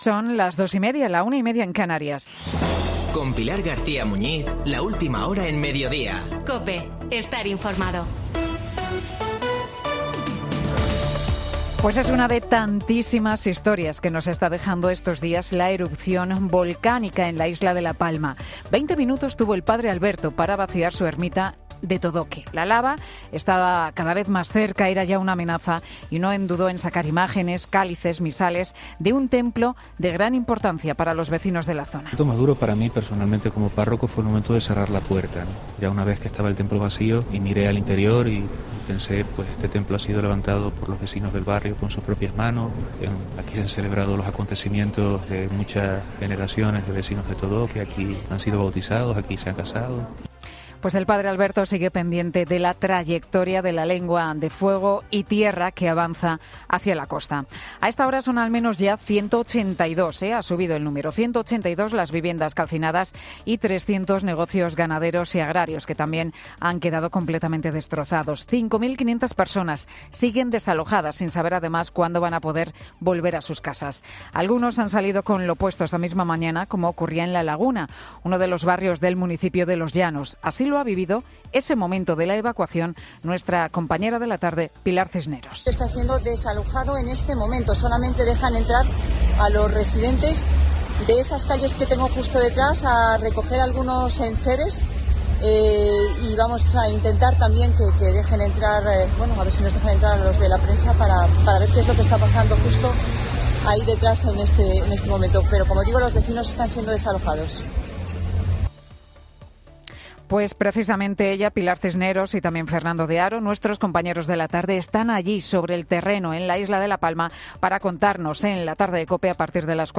El monólogo de Pilar García Muñiz en 'Mediodía COPE'